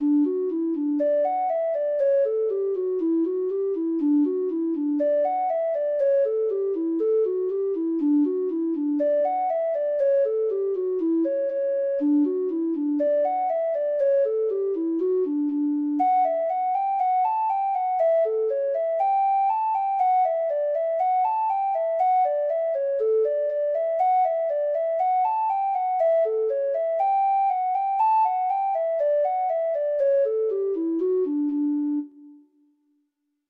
Free Sheet music for Treble Clef Instrument
Traditional Music of unknown author.
Reels
Irish